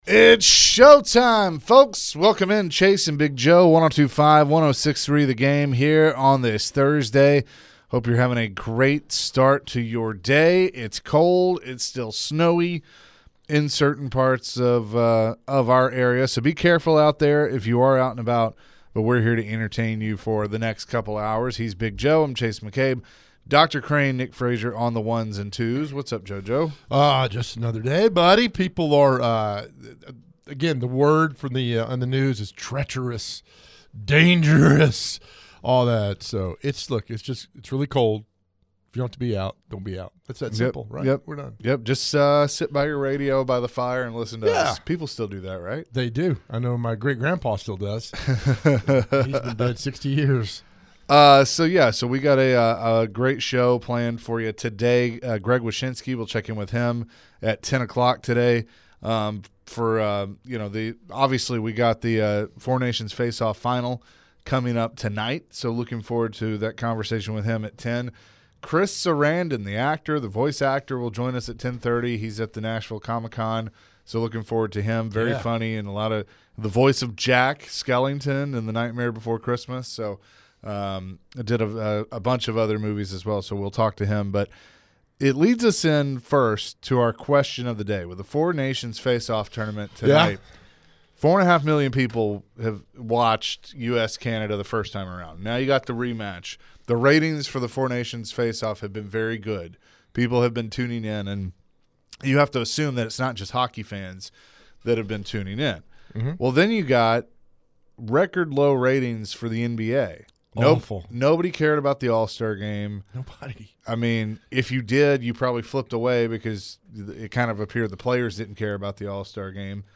Later in the hour, American actor Chris Sarandon joined the show as he will be at the Nashville Comic Con. To end the hour the guys posed the question, what's the most quotable movie of all time?